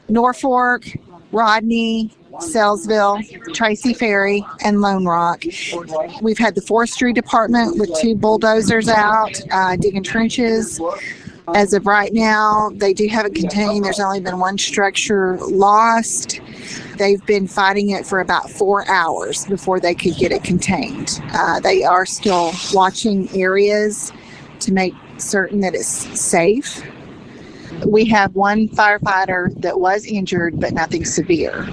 Norfork Mayor Lisa Harrison says multiple crews have been working to contain the blaze all afternoon.